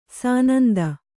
♪ sānanda